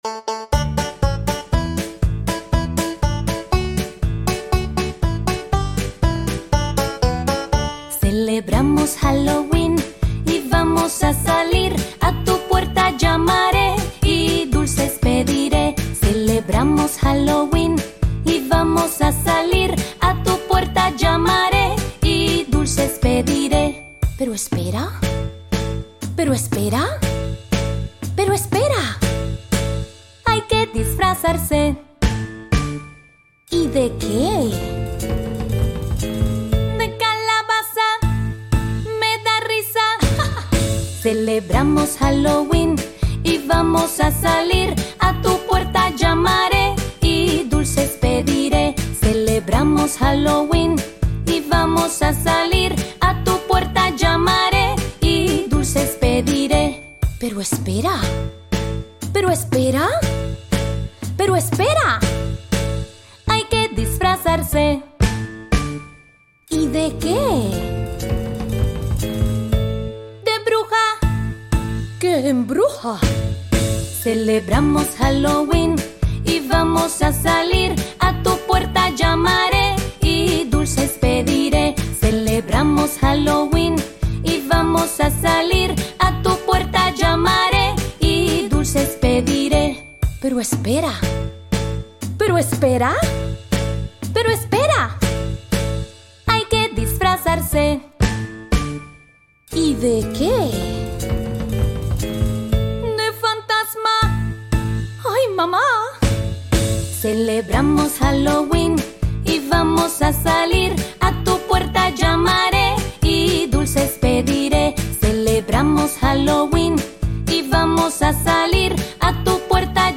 high-energy Spanish song